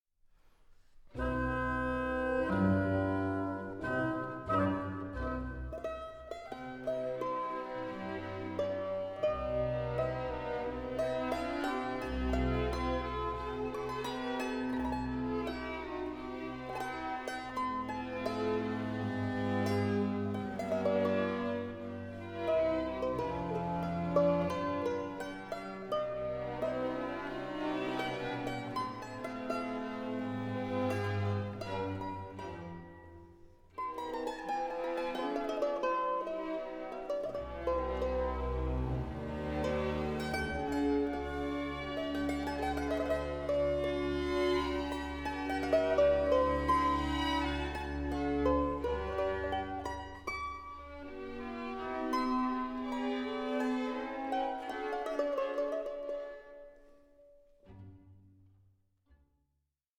for Mandolin and Orchestra